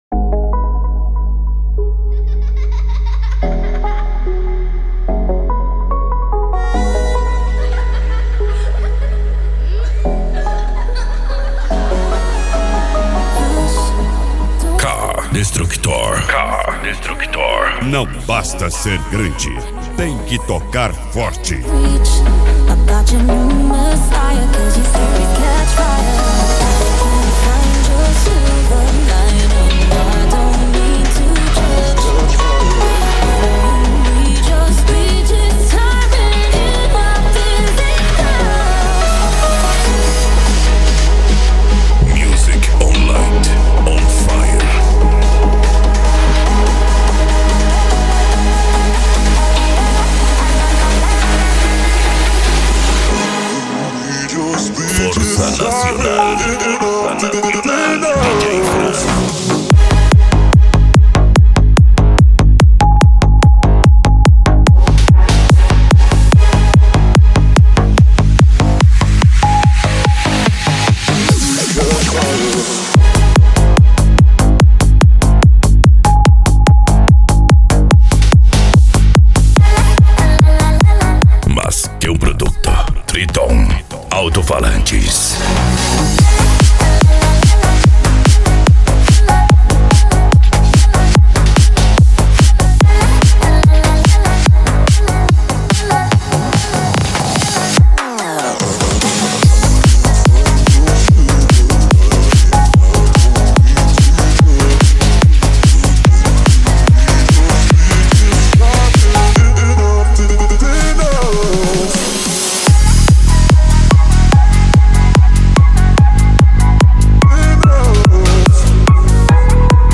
Bass
Psy Trance
Remix